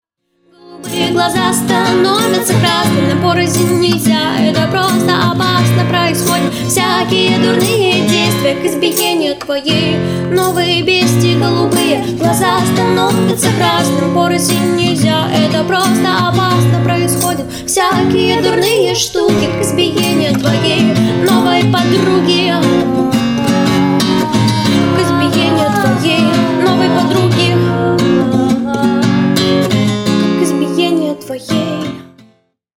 • Качество: 320, Stereo
гитара
веселые